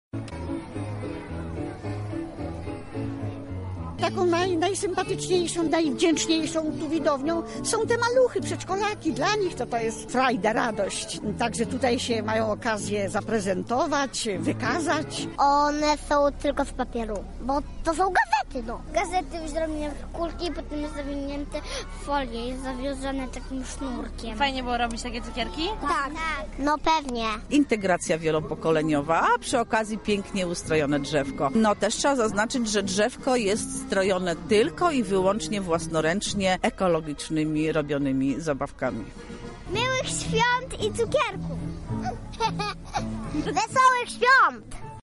Lubelskie przedszkolaki i mieszkańcy osiedla LSM wspólnie ubrali choinkę i zaśpiewali kolędy.